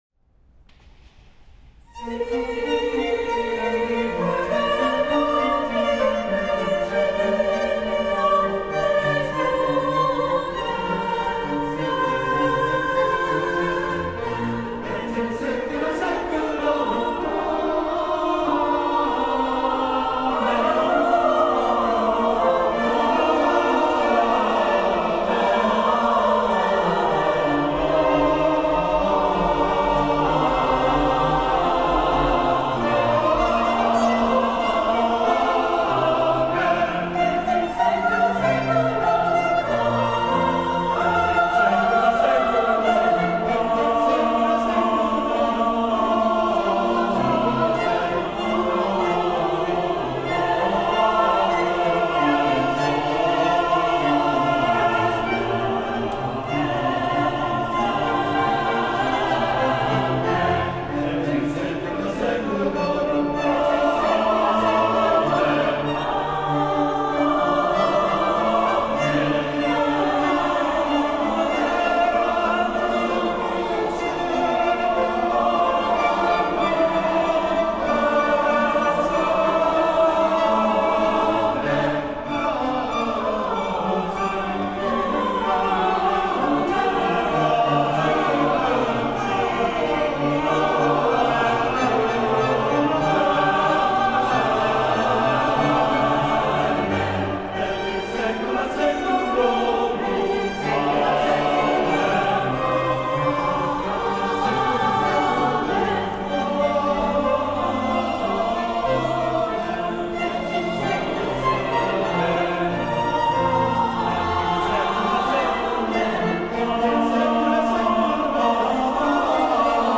Répétition SATB par voix
Tutti
Sicut Erat 4 voix.mp3